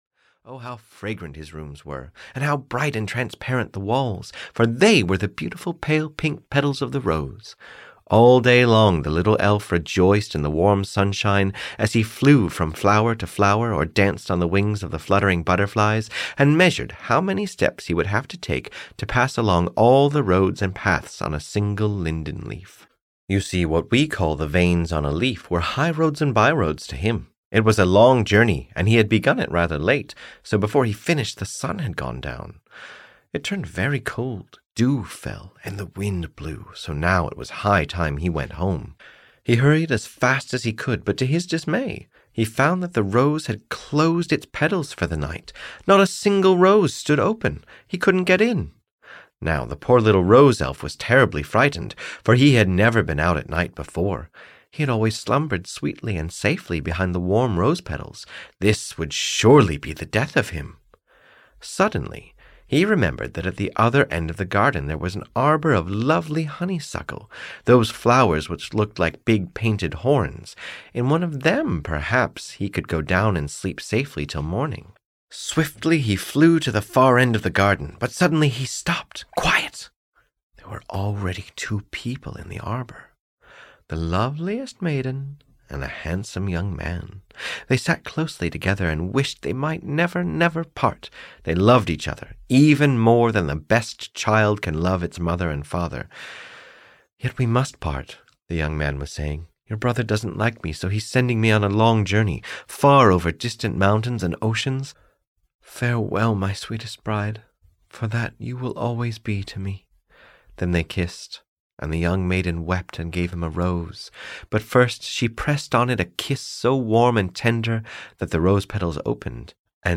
The Rose Elf (EN) audiokniha
Ukázka z knihy